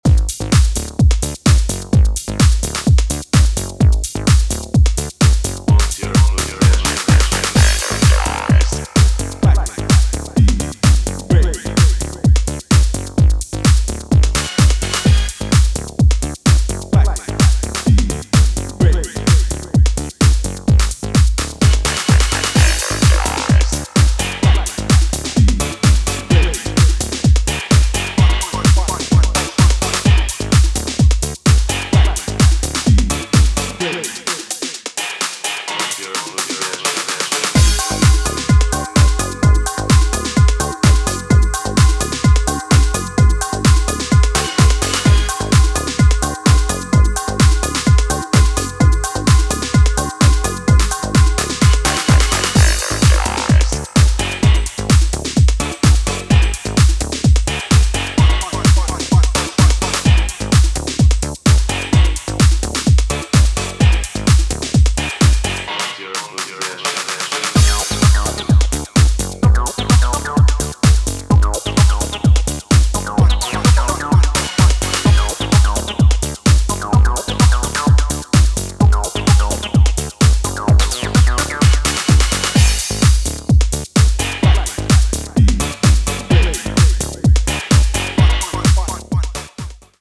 two bright, energetic tracks